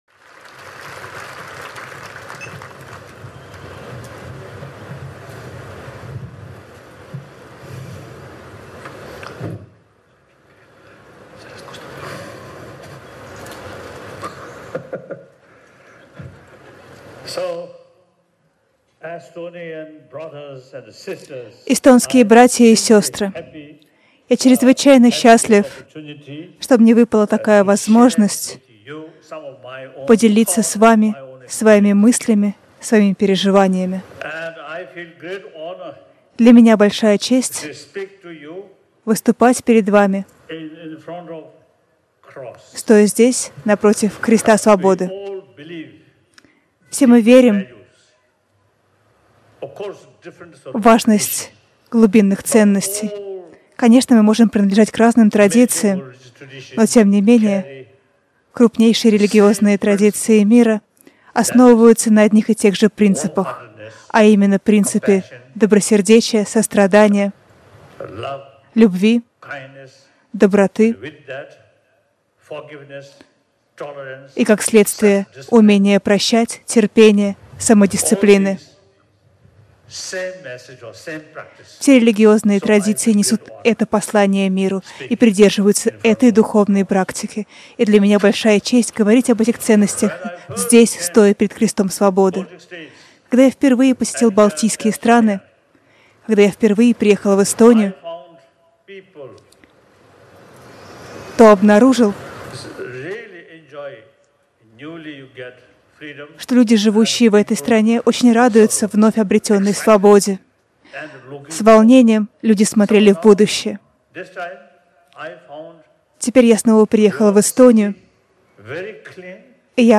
Аудиокнига Призыв к всеобщей ответственности | Библиотека аудиокниг